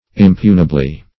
Search Result for " impunibly" : The Collaborative International Dictionary of English v.0.48: Impunibly \Im*pu"ni*bly\, adv. Without punishment; with impunity.